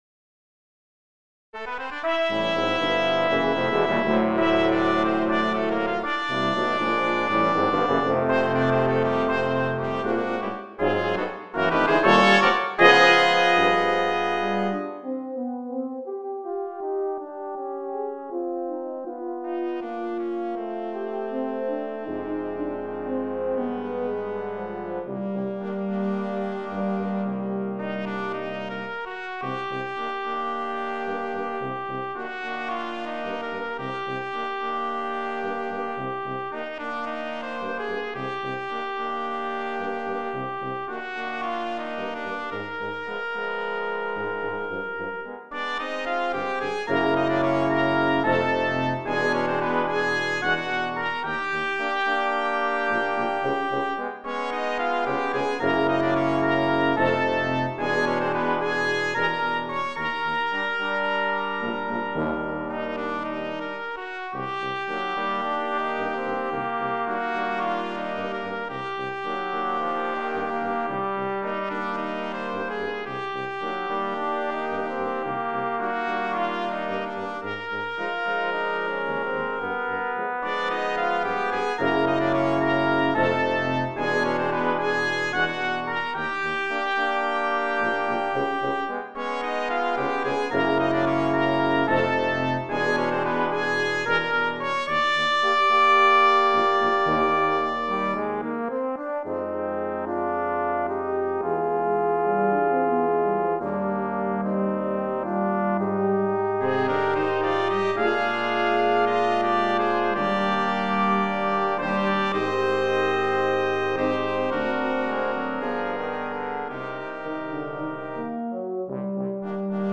Sextuor de Cuivres